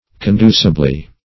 conducibly - definition of conducibly - synonyms, pronunciation, spelling from Free Dictionary Search Result for " conducibly" : The Collaborative International Dictionary of English v.0.48: Conducibly \Con*du"ci*bly\, adv. In a manner to promote.